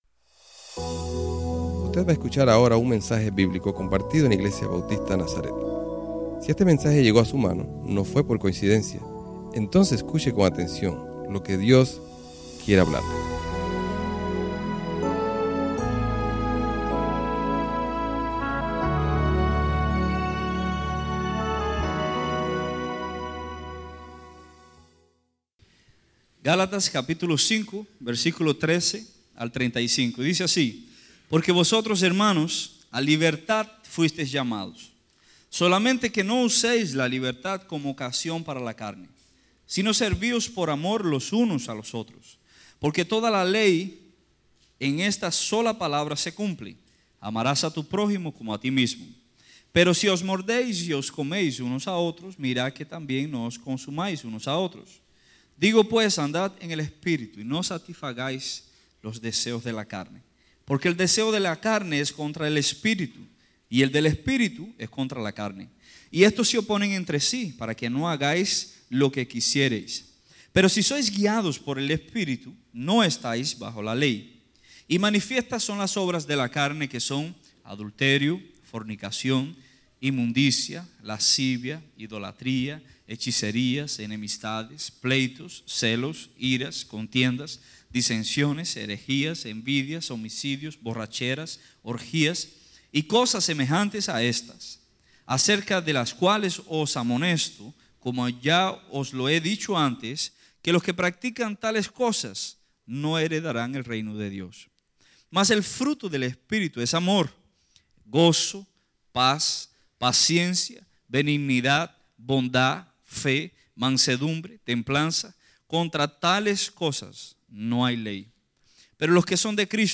Servicio Dominical